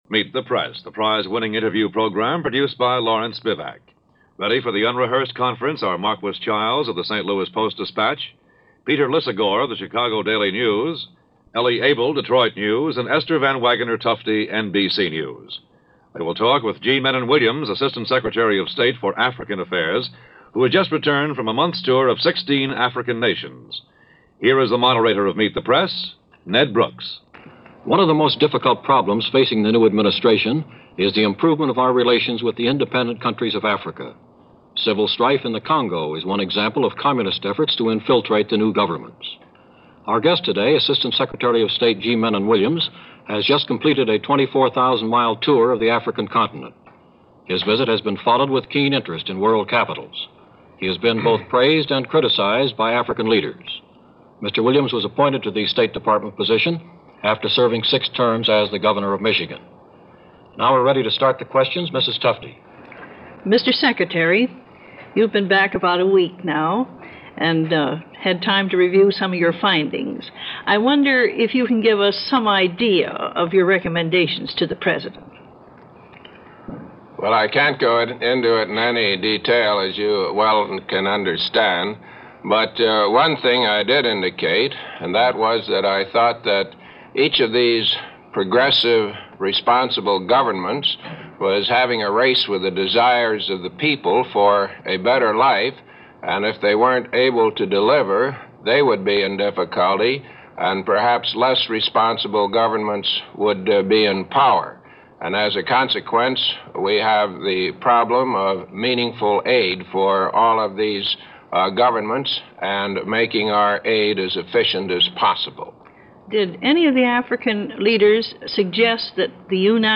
G. Mennen "Soapy" Williams Goes To Africa - 1961 - Meet The Press - 1961 - Newly appointed Assistant Sec. of State for African Affairs is interviewed